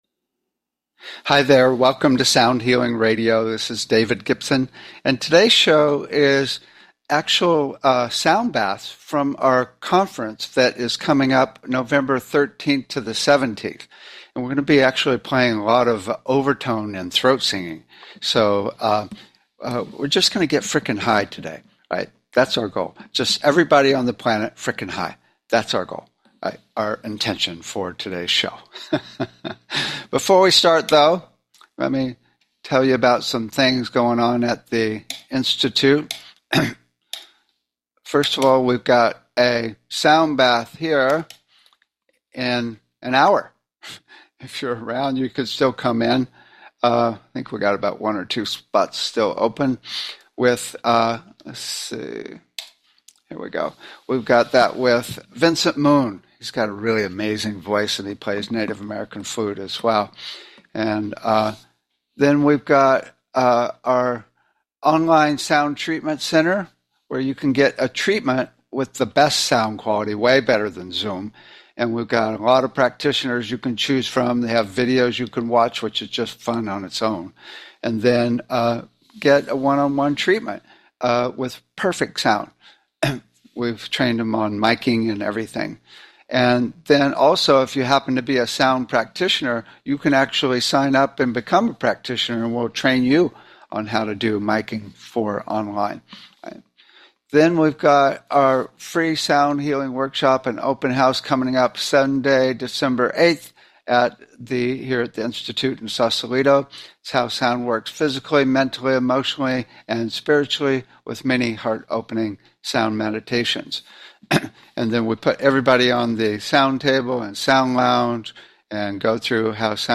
Talk Show Episode, Audio Podcast, Sound Healing and Sound Baths from Conference coming up on Nov 13 - 17 on , show guests , about Sound Baths, categorized as Education,Energy Healing,Sound Healing,Love & Relationships,Emotional Health and Freedom,Mental Health,Science,Self Help,Spiritual
Sound Baths from Conference coming up on Nov 13 - 17